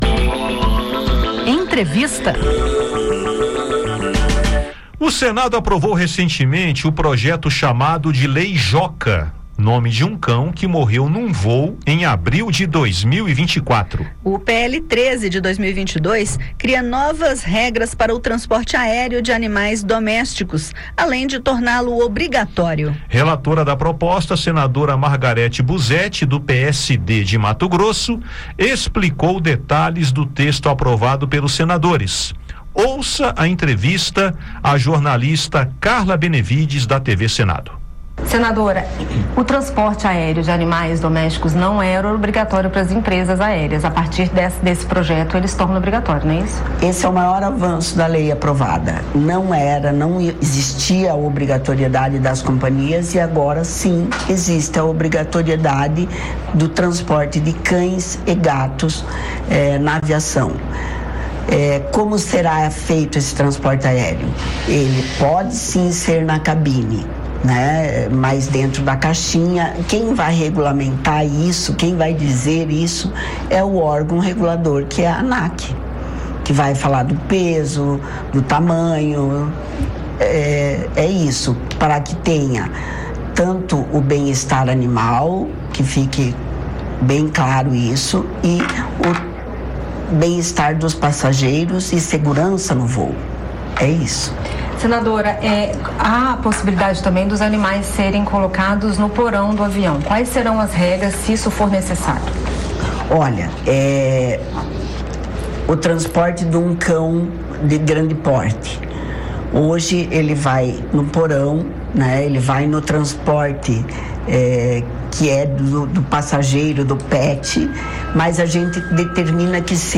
Foi aprovado recentemente no Senado o PL 13/2022, chamado de "Lei Joca", que cria novas regras para o transporte aéreo de animais domésticos. A senadora Margareth Buzetti (PSD-MT), relatora da proposta, conversou com a jornalista